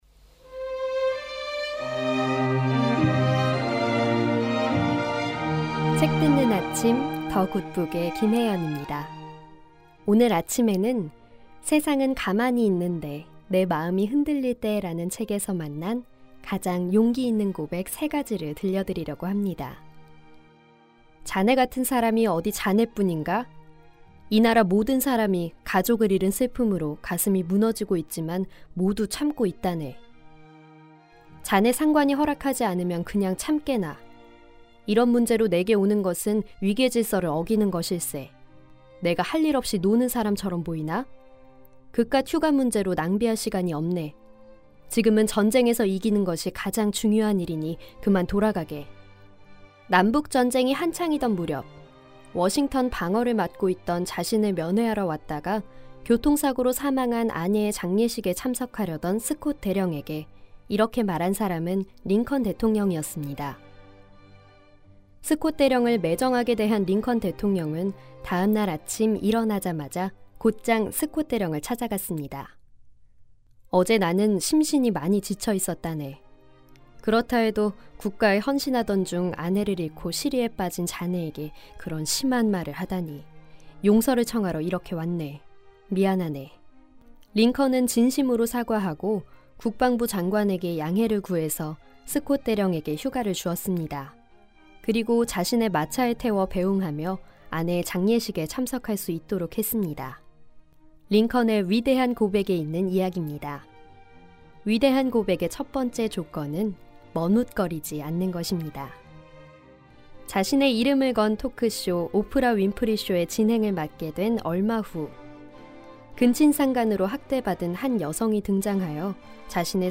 북 큐레이터